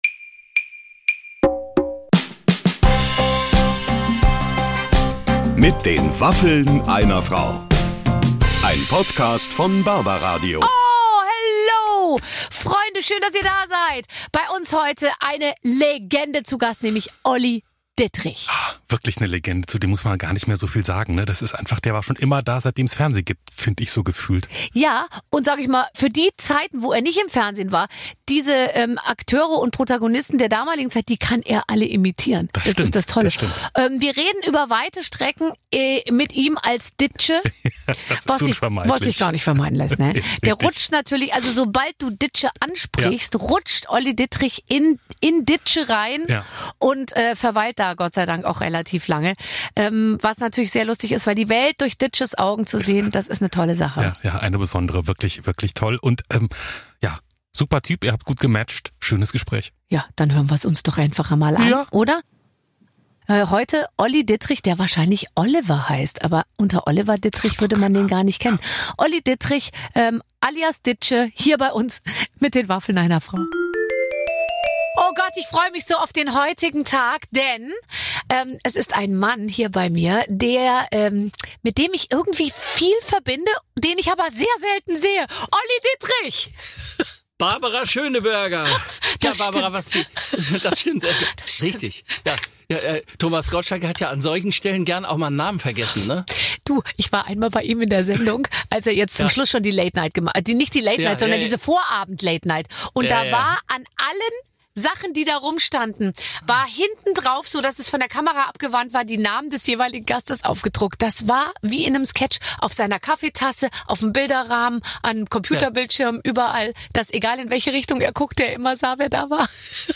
Olli Dittrich plaudert mit Barbara Schöneberger genüsslich über seine Zeit mit Loriot, seinen Opa und natürlich Dittsche! Ansonsten muss er noch als Lastenfahrrad Ludwig einen Anrufbeantworter besprechen und erzählt von seiner Zeit als 16-jähriger Rockstar.